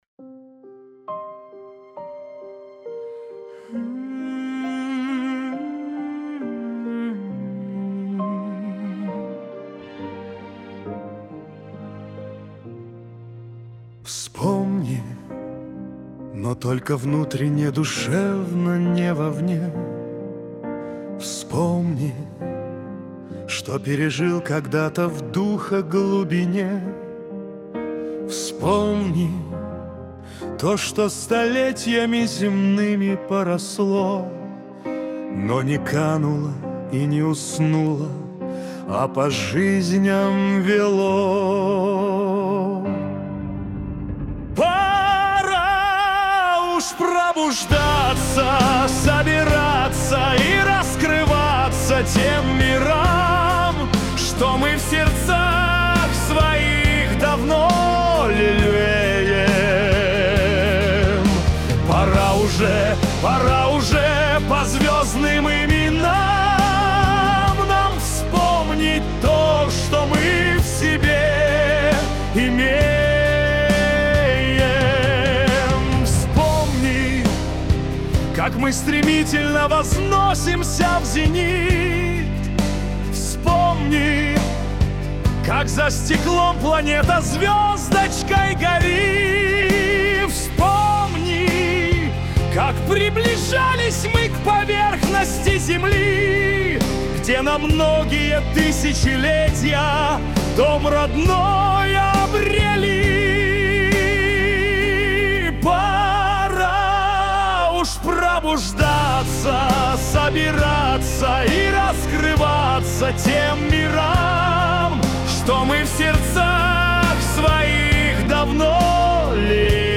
Для Медитаций